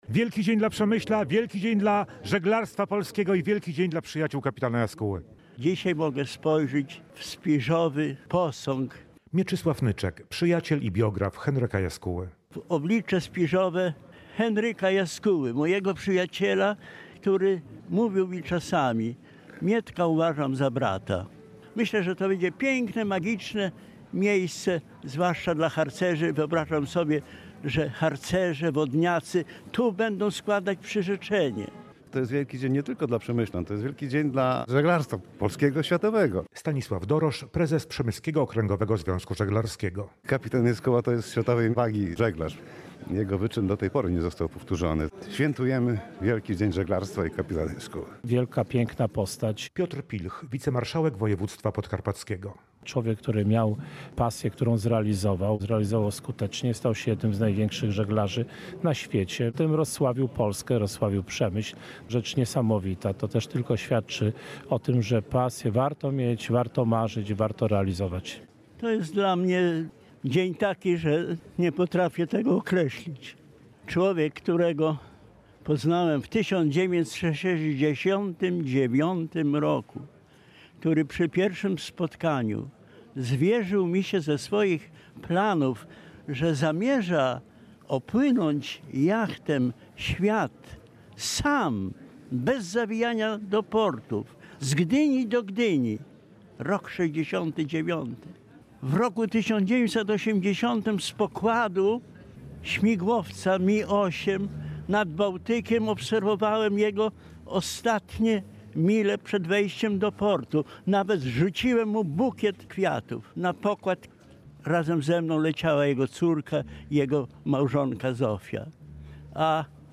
Dziesiątki przemyślan zgromadziły się w południe na ulicy Franciszkańskiej, aby wziąć udział w odsłonięciu posągu wybitnego żeglarza, kapitana Henryka Jaskuły, honorowego obywatela miasta.